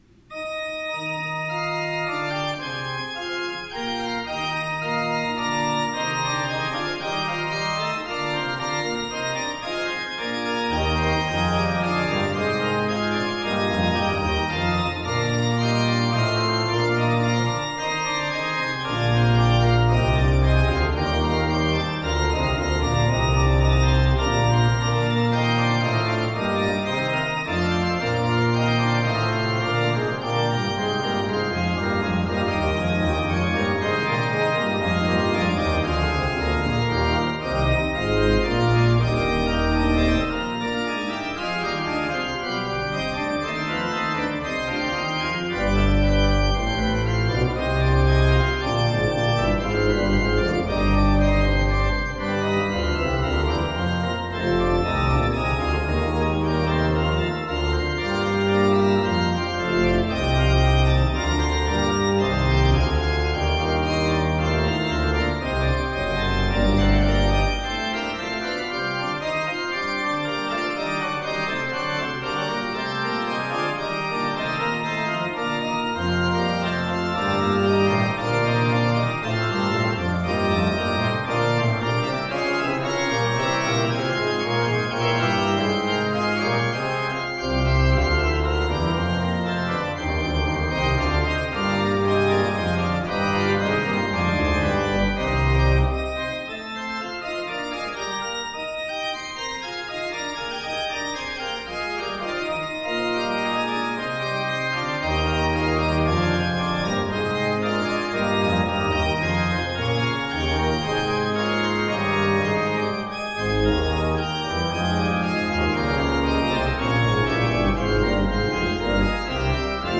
Organ  (View more Intermediate Organ Music)
Classical (View more Classical Organ Music)